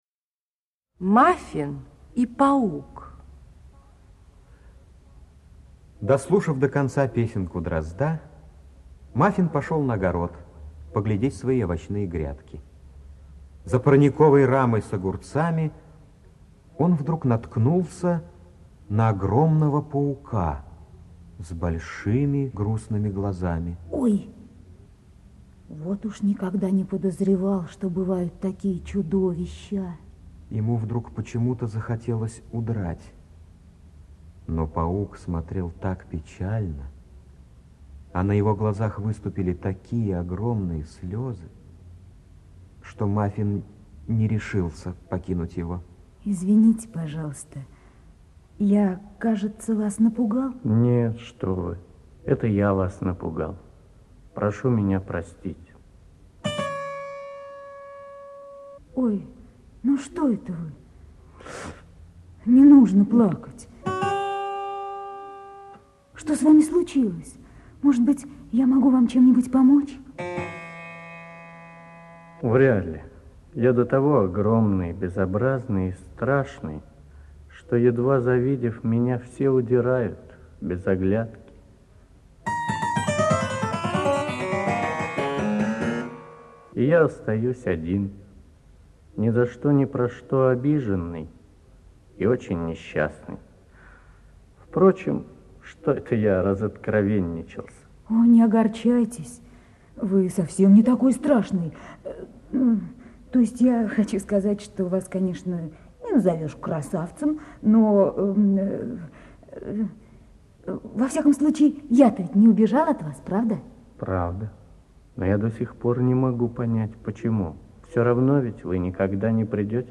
Мафин и паук - аудиосказка Хогарт - слушать онлайн